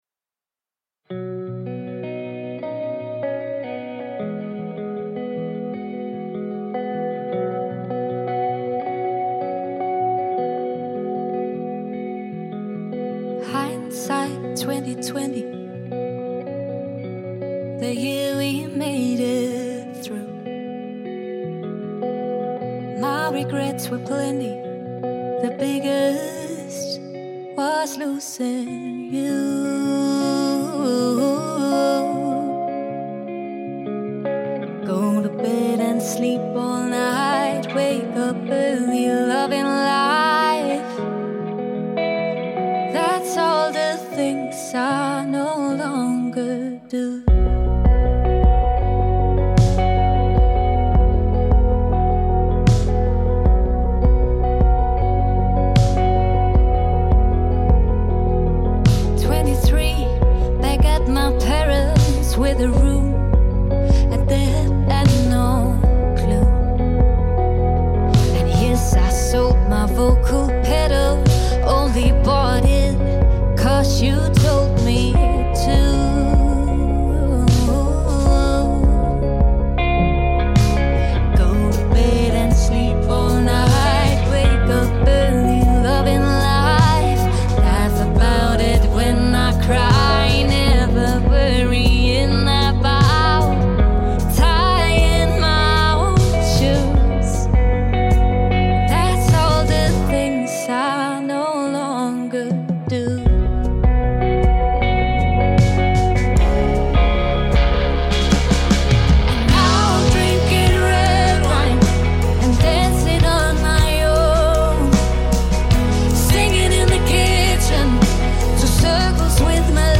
• Country
• Folk
• Pop
• Soul
Vokal
Klaver
Trommer